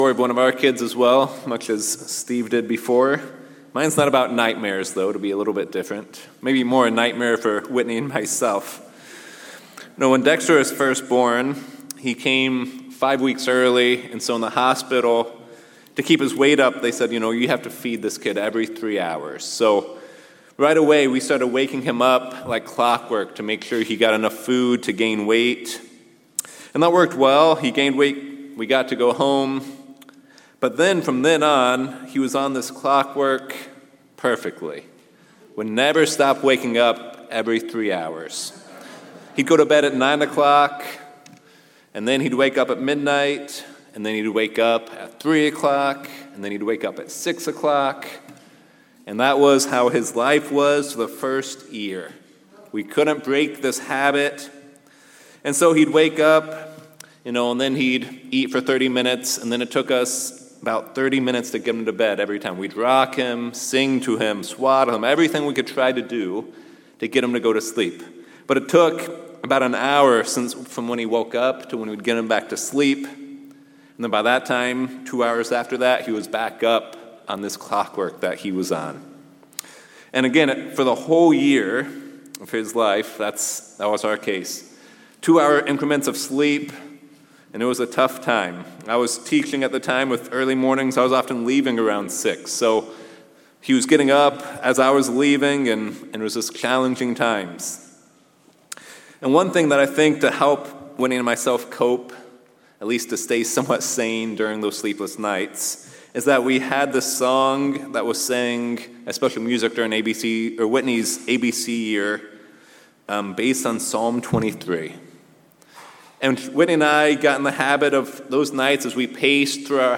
It is a shepherd psalm written by David with elements of our walk with God and journey in life. This will be a multi-part sermon to dive deep into this Psalm and learn what David wants us to get out of it.